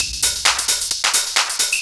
DS 132-BPM A3.wav